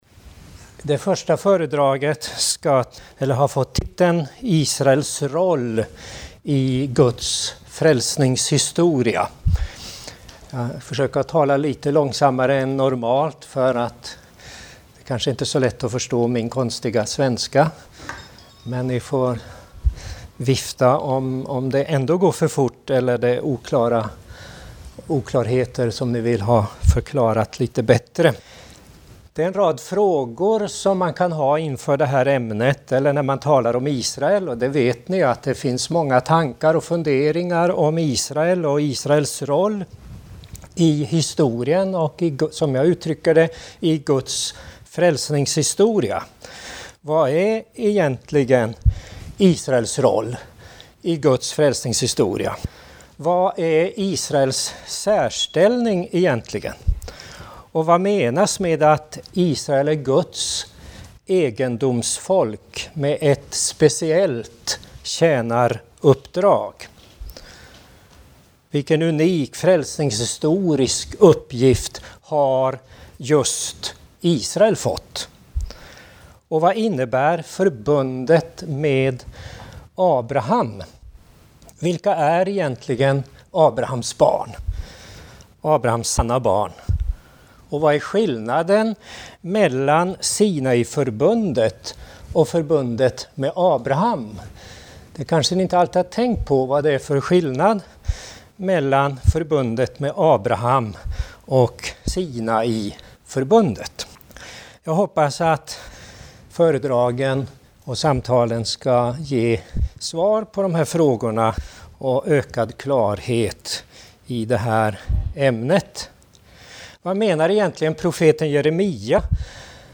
Hør flere foredrag fra Biblicum-dager i Stavanger